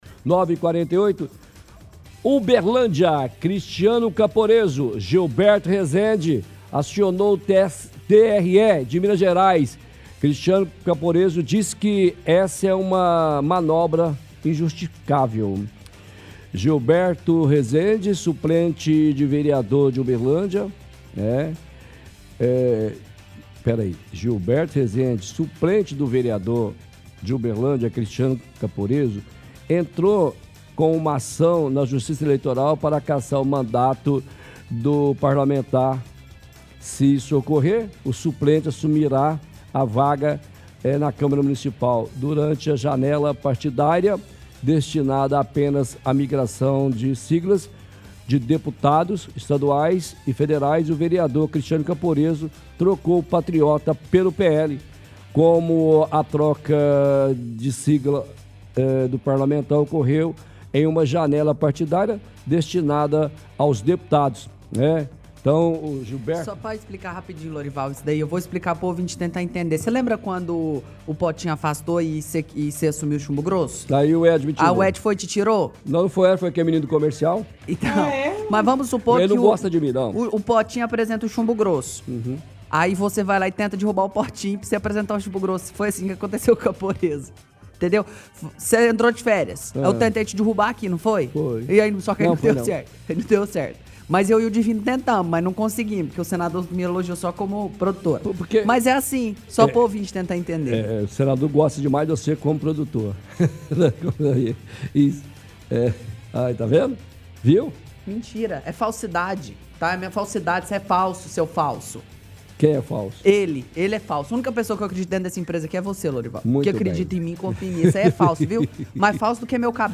lê reportagem sobre ação para cassação do mandato do vereador Cristiano Caporezzo por troca partidária.